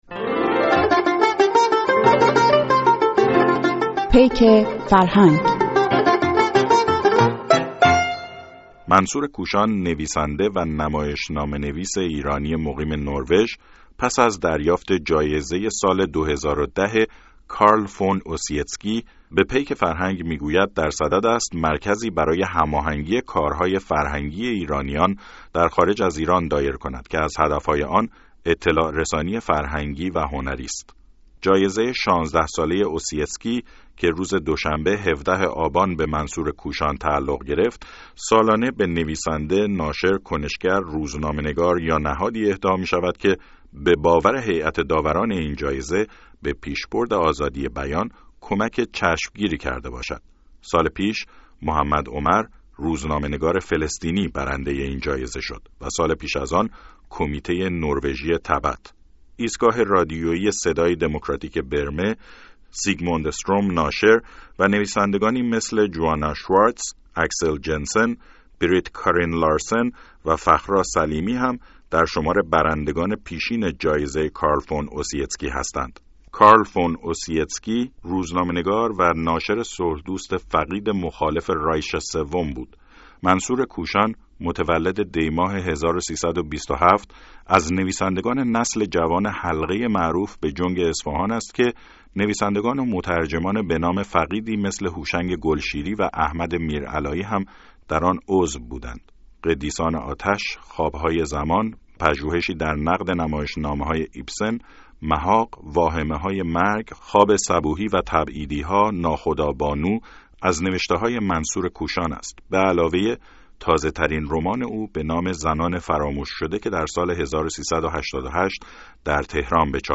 گفت‌وگوی پیک فرهنگ رادیوفردا با منصور کوشان